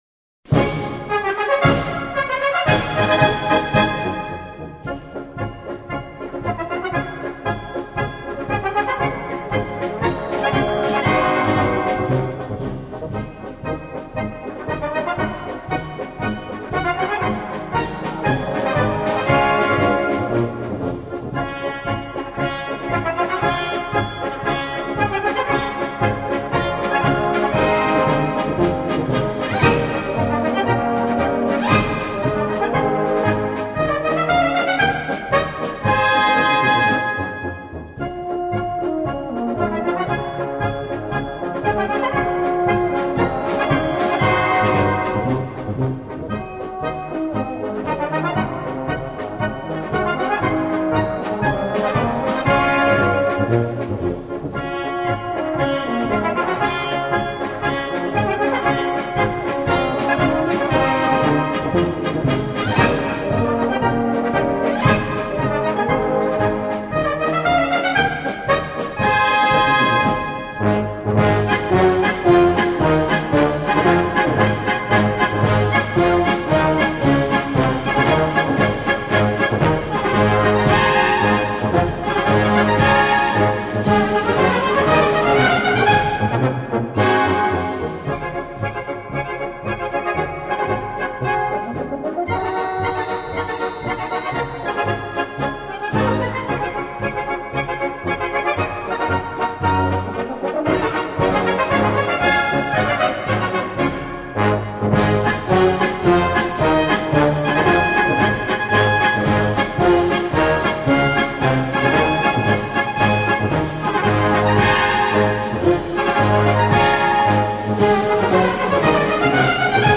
Marches Prussiennes